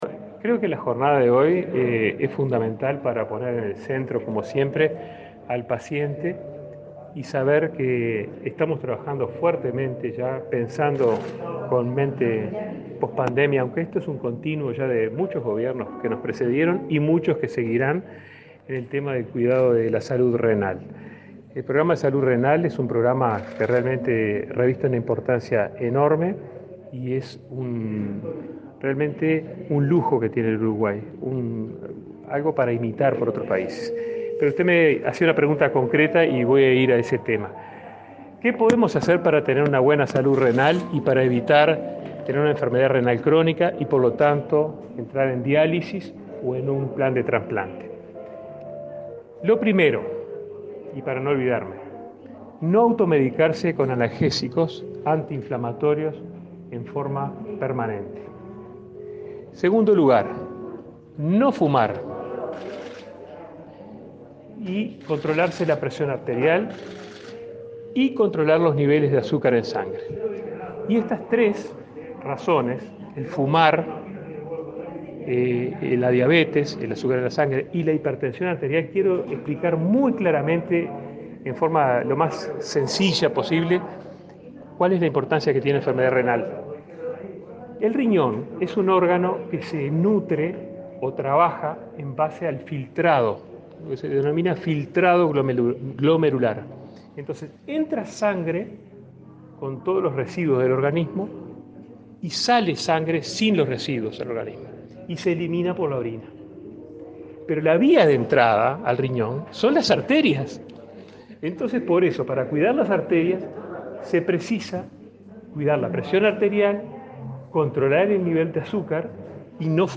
Declaraciones a la prensa del ministro de Salud Pública, Daniel Salinas
Declaraciones a la prensa del ministro de Salud Pública, Daniel Salinas 10/03/2022 Compartir Facebook X Copiar enlace WhatsApp LinkedIn El ministro de Salud Pública, Daniel Salinas, participó del acto por el Día Mundial del Riñón, realizado este jueves 10 en el Fondo Nacional de Recursos. Luego, dialogó con la prensa.